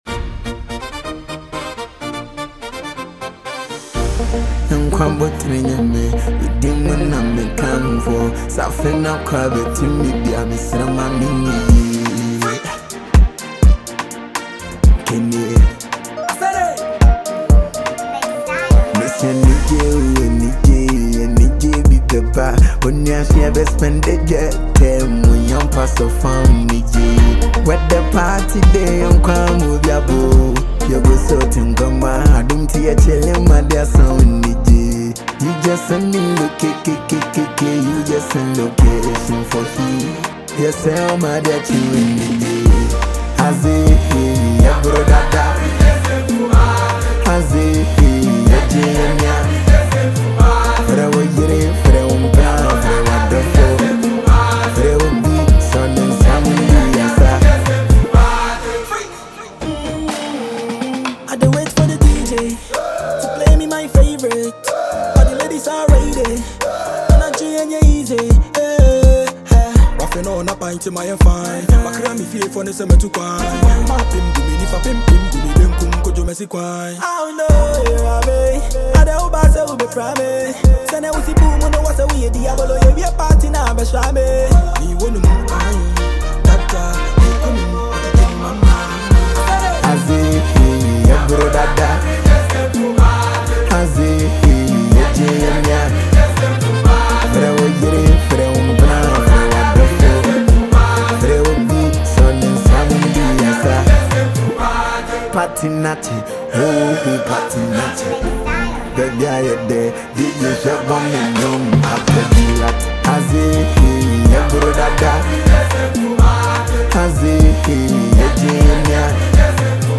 Ghanaian singer
sets a calm and positive mood
heartfelt vocals